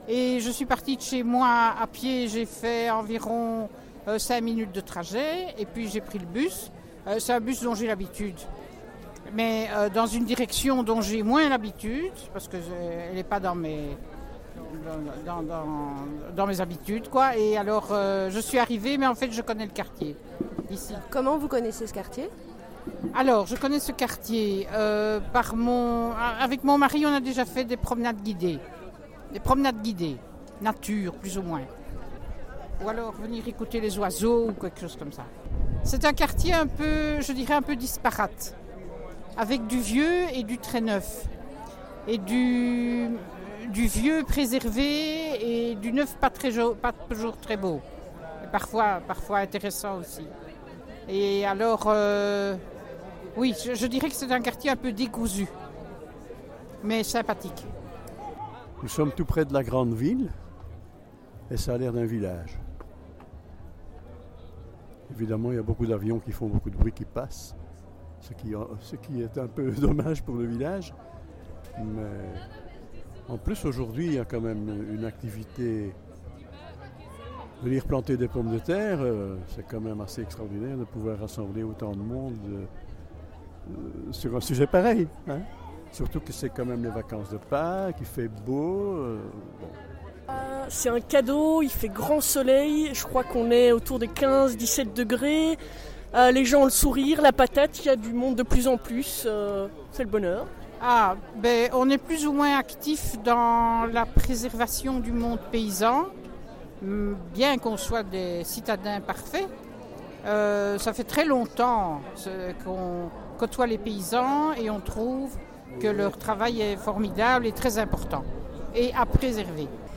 Extraits sonores de la journée : Patador.mp3